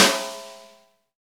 SNR XEXTS06L.wav